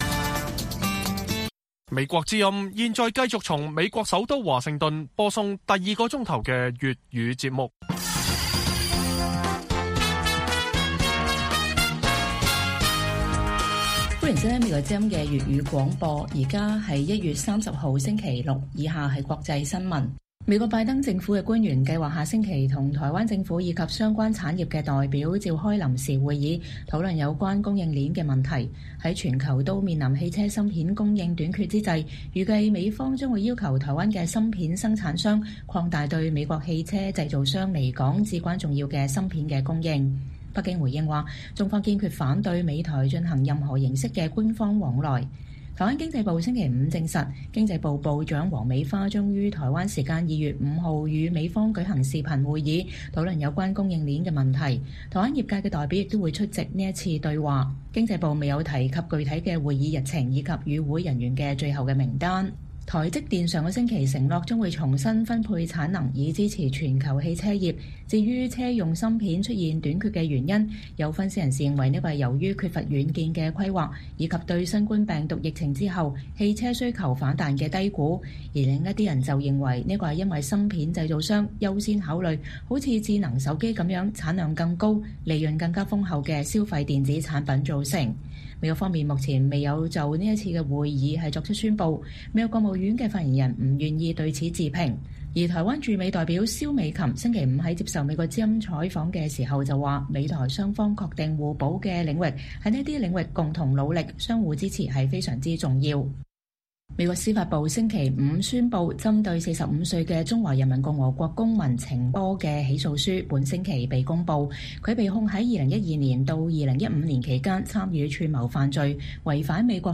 北京時間每晚10－11點 (1400-1500 UTC)粵語廣播節目。內容包括國際新聞、時事經緯和英語教學。